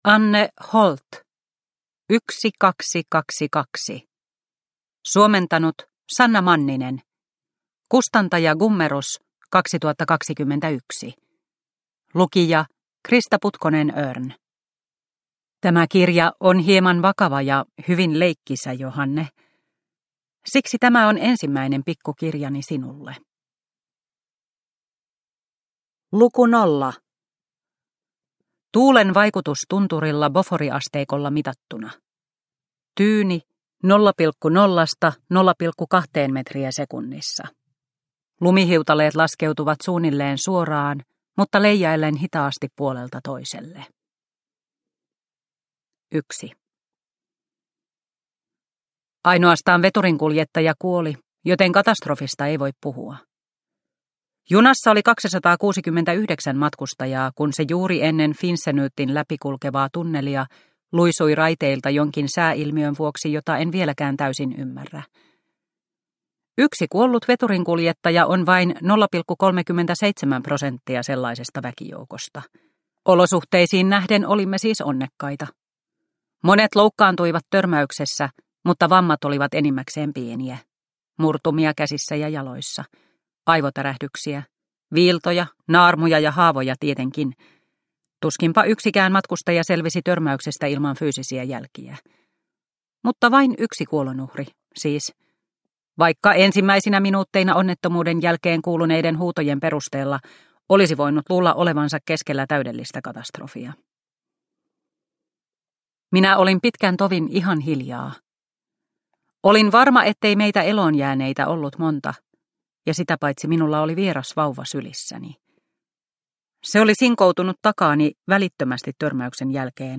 1222 – Ljudbok – Laddas ner